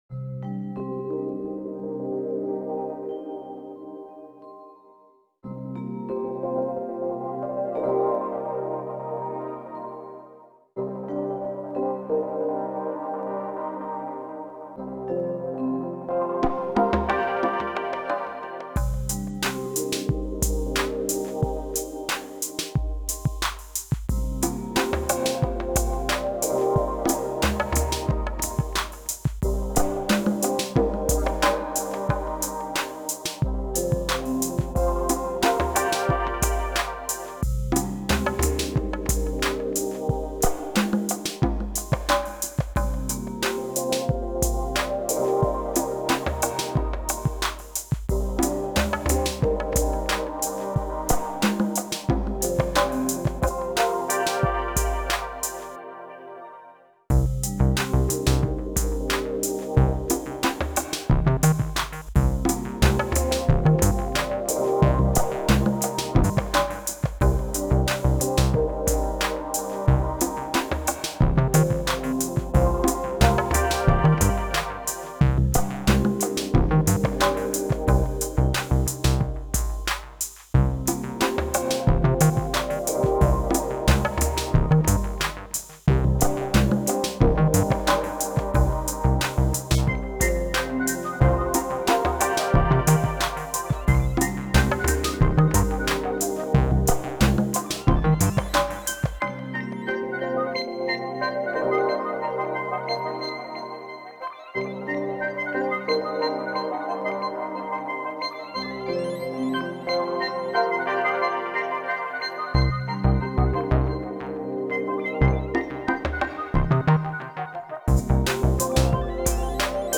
Genre= Ambient